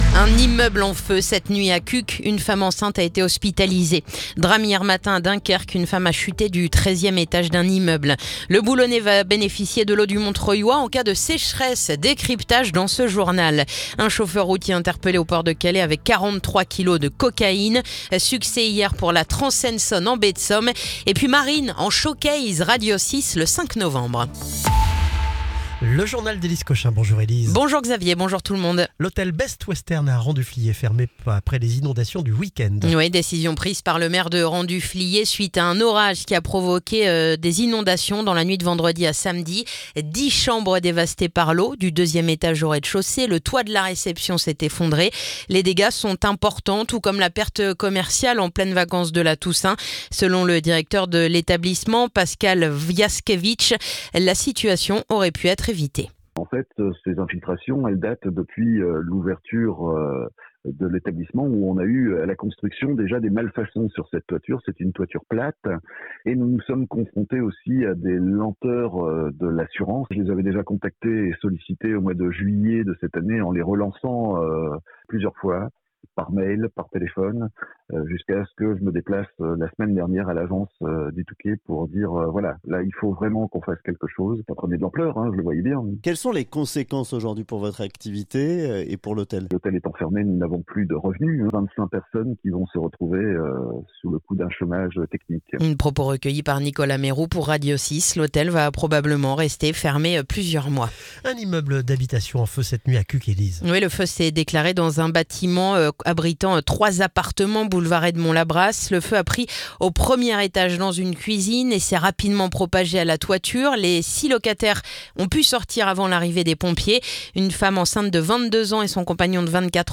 Le journal du lundi 27 octobre